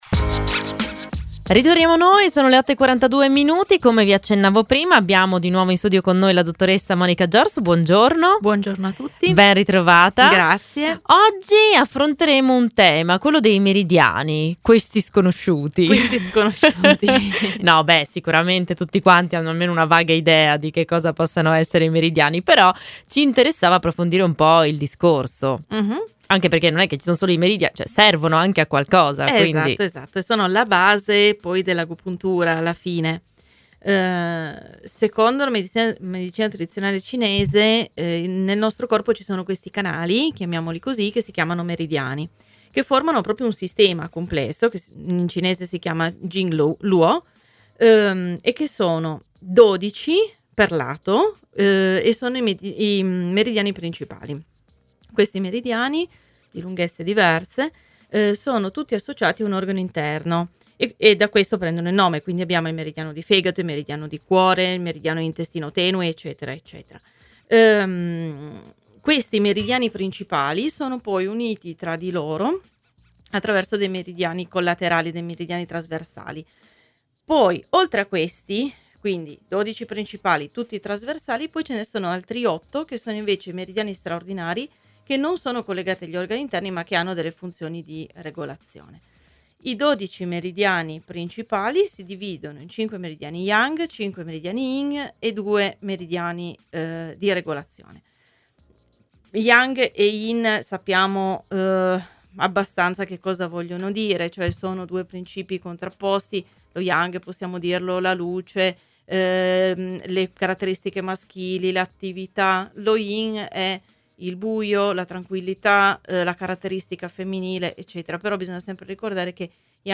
Home Intervista Agopuntura